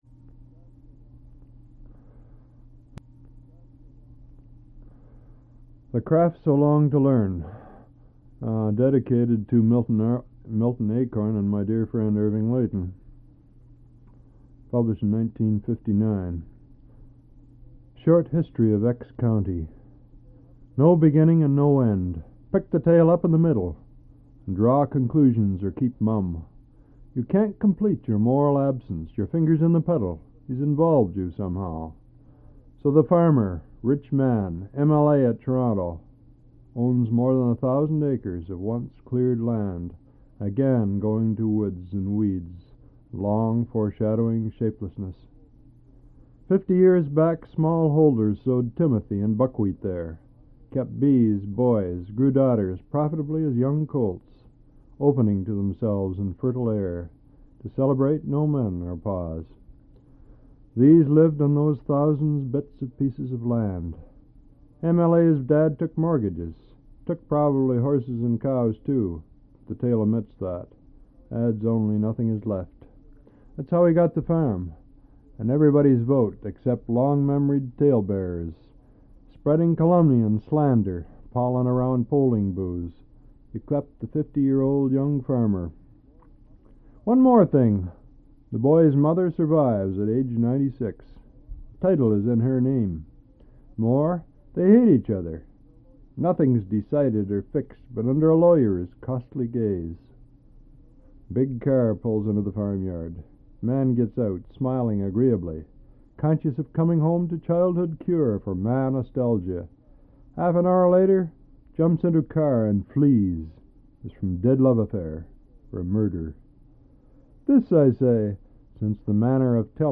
Al Purdy reads his poetry; TOC: Short History of X County 0:18; Villanelle [Plus 1] 3:02; After the Rats 5:29; All About Pablum, Teachers, and Malcolm Lowry 6:48; Where the Moment Is 8:30; Canadian New Year Resolutions 9:43; Olympic Room 11:08; Driftwood Logs 12:04; From the Chin P'ing Mei 13:19; Rain Poem 14:12; For Oedi-Puss 14:59; Waiting for an Old Woman to Die 17:11; On the Decipherment of "Linear B" 18:28; Flashback 20:58; At Roblin Lake 21:24; Vestigia 23:41; If Birds Look In 24:31; At Evergreen Cemetery 25:23; Visitors 26:21; Passport 27:40; Love Song 28:42; Total Duration 29:59.